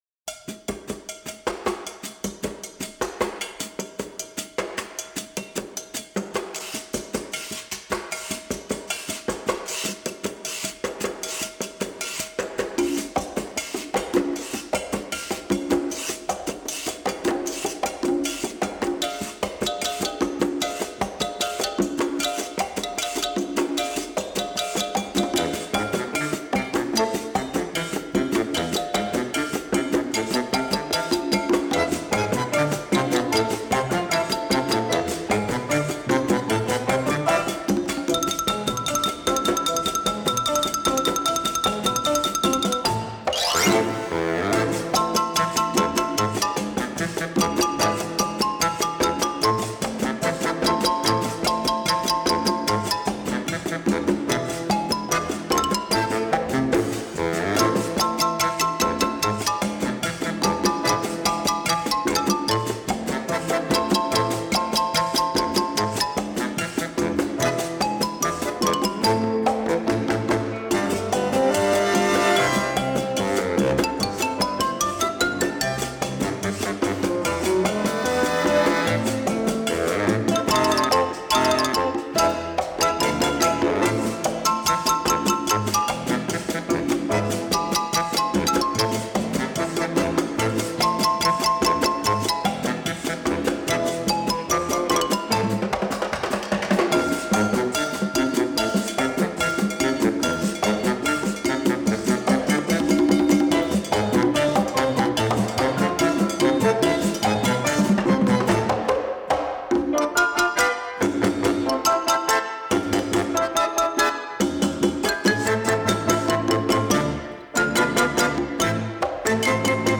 здесь чудесные челесты имитирующие звонок телефона!